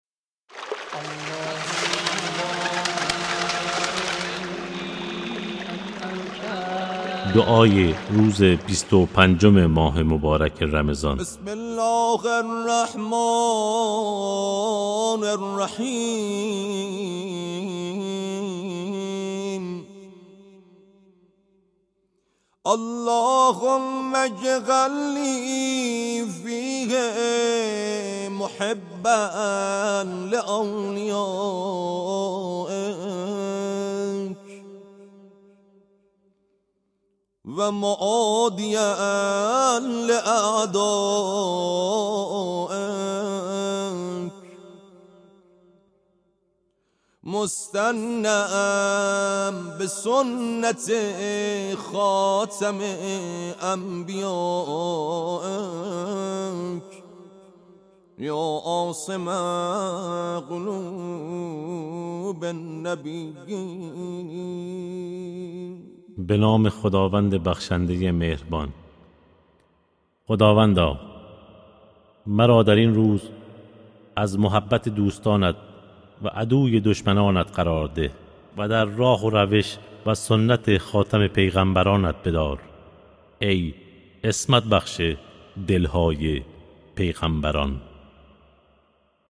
دعای روزهای ماه مبارک رمضان